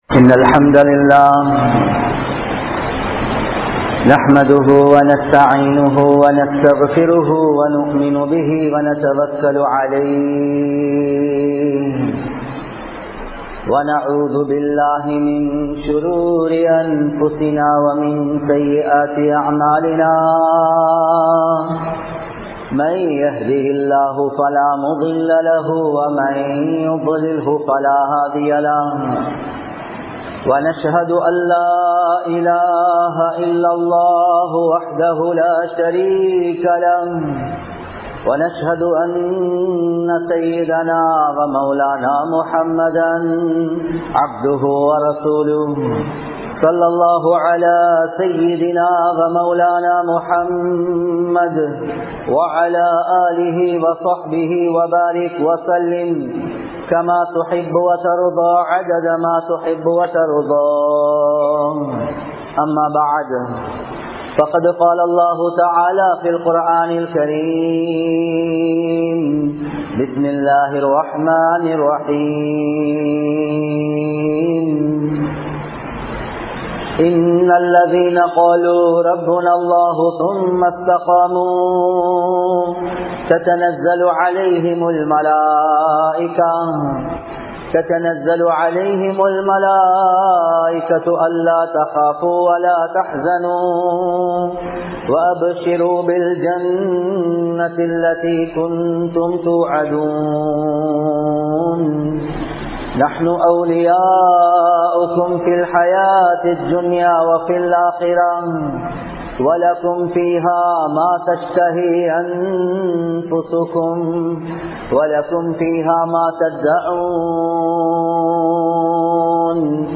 Kulanthai Valarpum Pettroarhalum (குழந்தை வளர்ப்பும் பெற்றோர்களும்) | Audio Bayans | All Ceylon Muslim Youth Community | Addalaichenai
Colombo 07, Jawatha Jumua Masjith